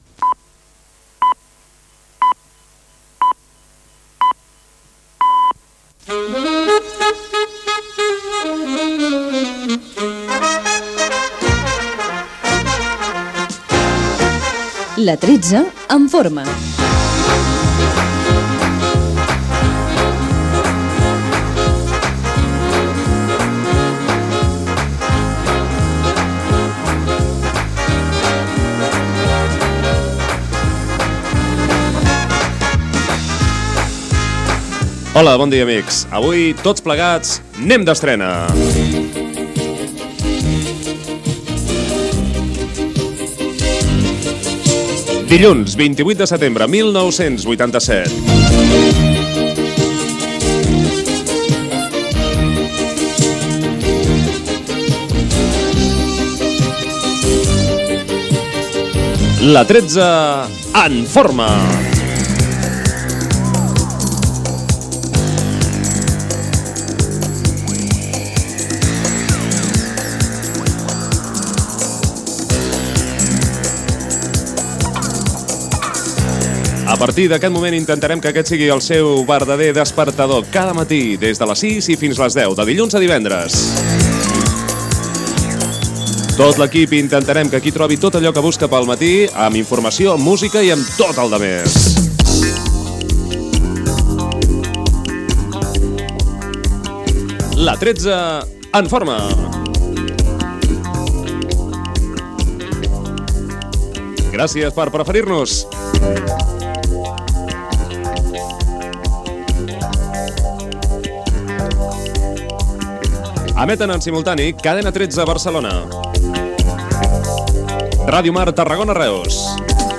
Careta del programa, salutació inicial, emissores que formen part de la Cadena 13, equip del programa i indicatiu.
Entreteniment
FM